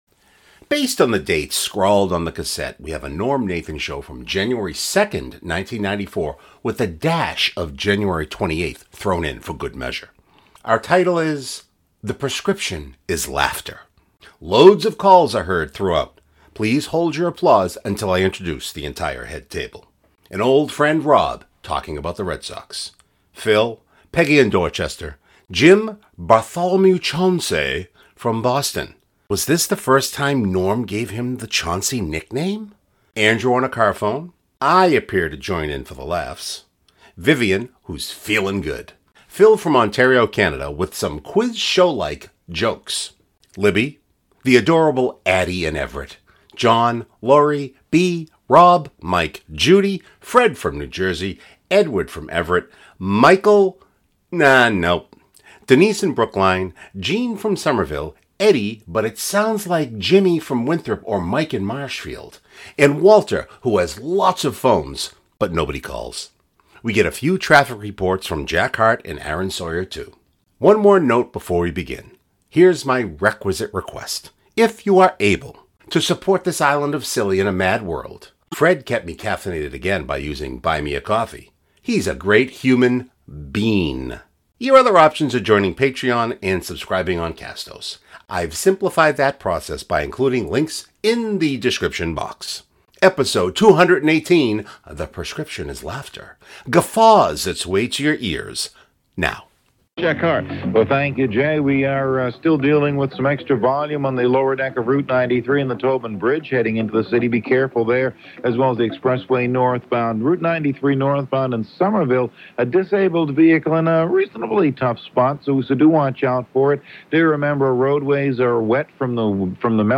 Our title is: The Prescription is Laughter Loads of calls are heard throughout.